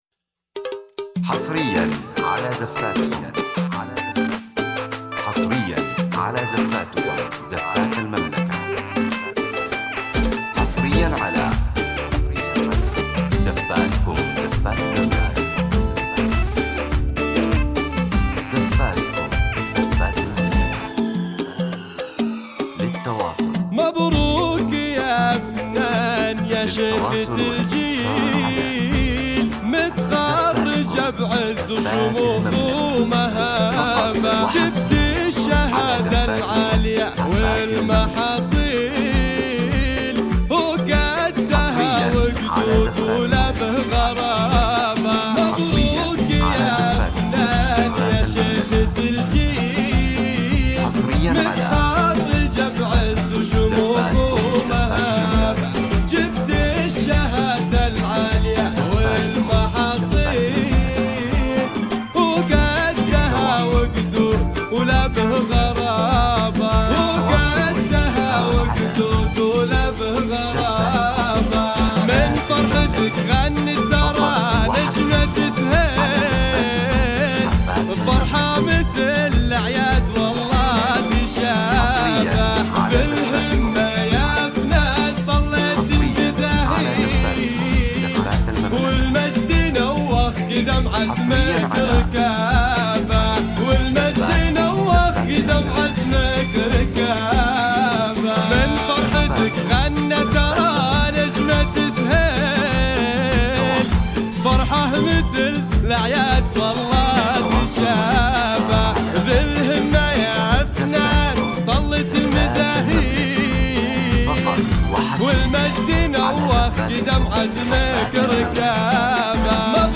بتنسيق احترافي وإيقاع فخم لحفلات الخريجات.
زفة فخمة بإيقاع موسيقي راقٍ تعبر عن الفخر والفرح بالنجاح.